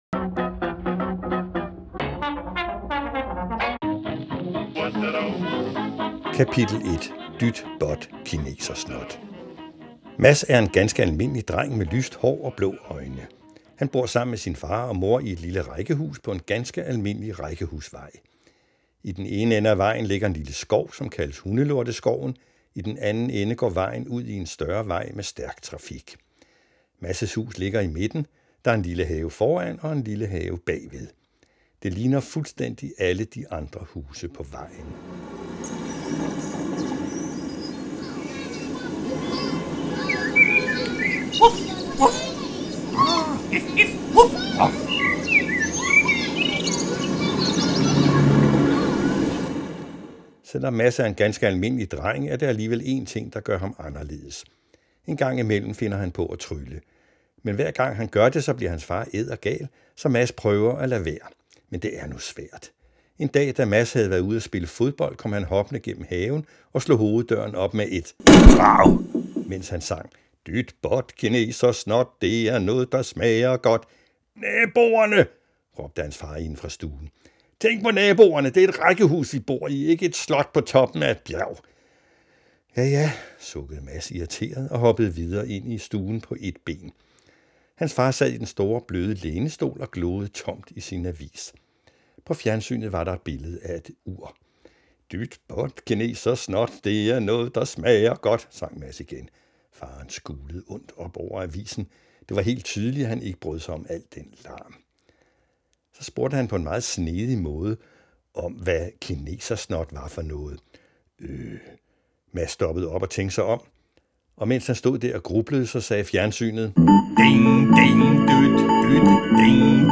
Den første gang Magiske Mads viste sig var i 1978, hvor han dukkede op i en række små radiofortællinger, som var en slags radiotegneserie med mig, Gunnar Wille, som fortæller og krydret med energiske og groteske lydbilleder. Det var mit gennembrud som forfatter og blandingen af de tegnefilmsagtige lyde og festlig medrivende jazz, var på det tidspunkt overraskende og nyskabende.
Lydbøgerne